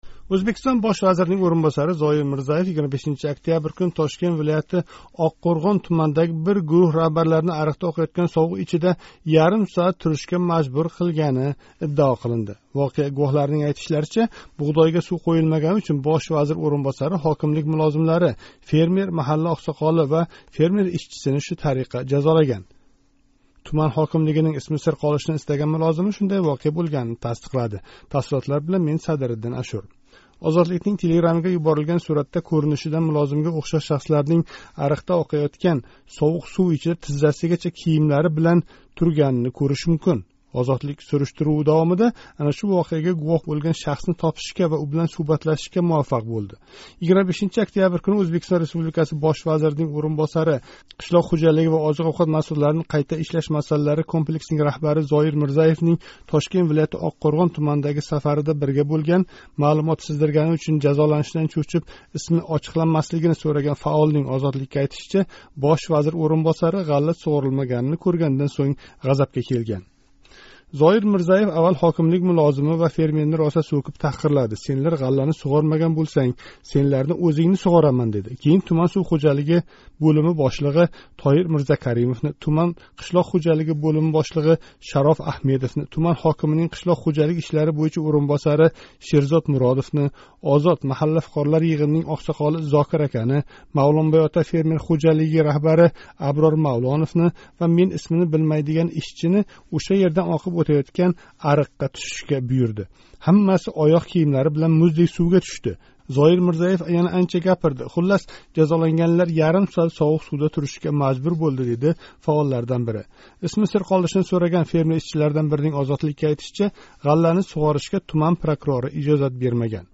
Ҳодиса гувоҳи билан суҳбат